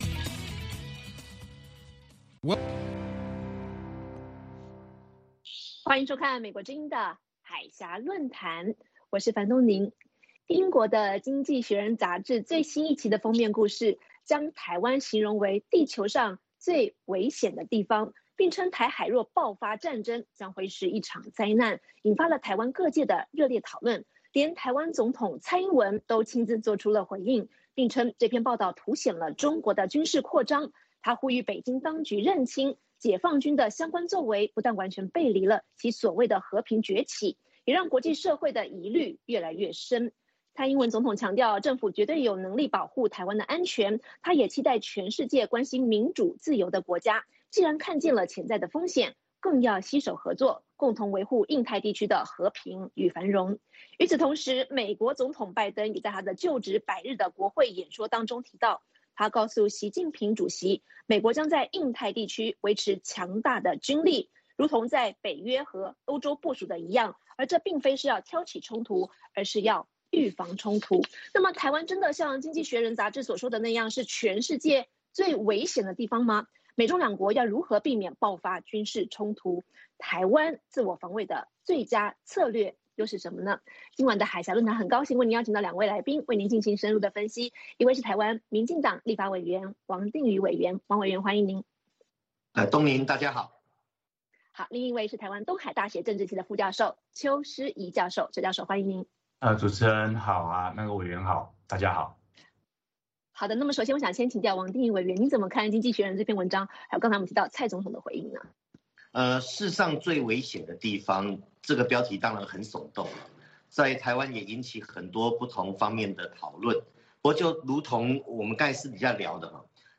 《海峡论谈》节目邀请华盛顿和台北专家学者现场讨论政治、经济等各种两岸最新热门话题。